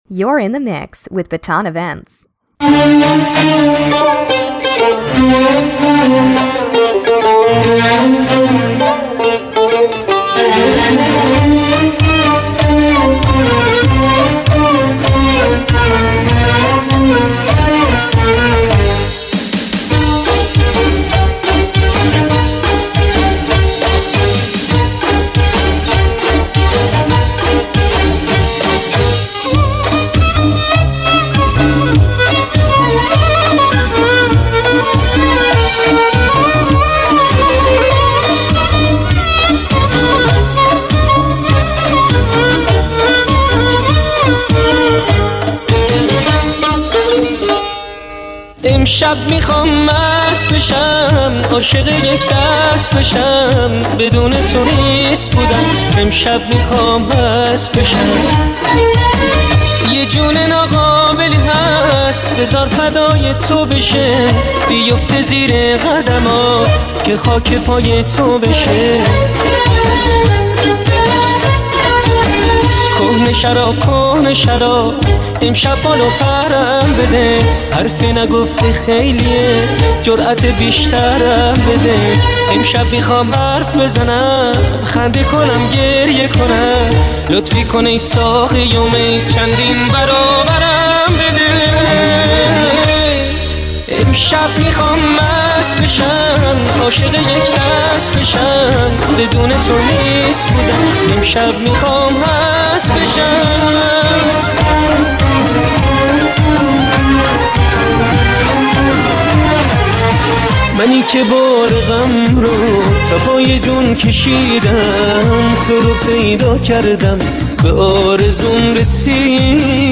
Venezuelan Mix Hebrew Mix Arabic Mix Persian Mix